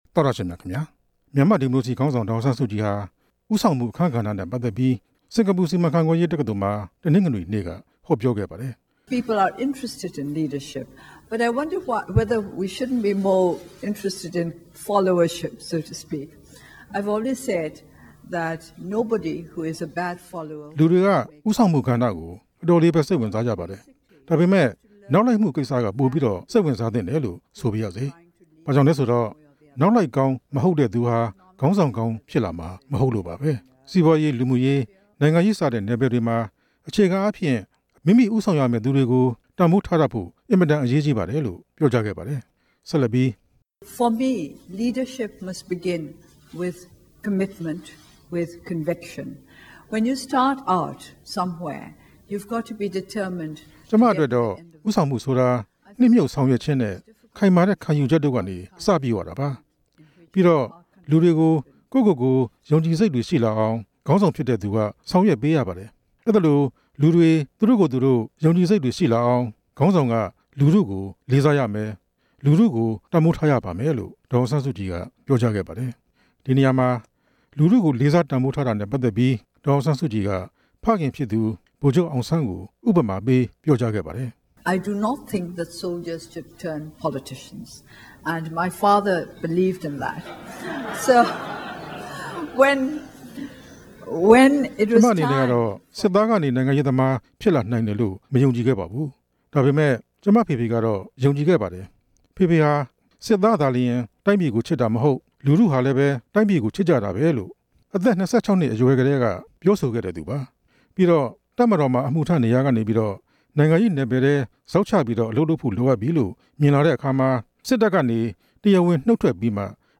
ဒေါ်အောင်ဆန်းစုကြည်ရဲ့ ဦးဆောင်မှု အခန်းကဏ္ဍ ဟောပြောချက်
ဒေါ်အောင်ဆန်းစုကြည်ဟာ တနင်္ဂနွေနေ့က စင်္ကာပူ စီမံခန့်ခွဲရေးတက္ကသိုလ်မှာ ဦးဆောင်မှု အခန်းကဏ္ဍနဲ့ ပတ်သက်ပြီး ဟောပြောခဲ့ပါတယ်။
ဟောပြောပွဲကို ကျောင်းသူကျောင်းသားတွေ၊ တက္ကသိုလ်ဆရာ ဆရာမတွေ၊ စီးပွားရေးလုပ်ငန်းရှင်တွေ စုစုပေါင်း ၆ဝဝ လောက် တက်ရောက်ခဲ့ကြပါတယ်။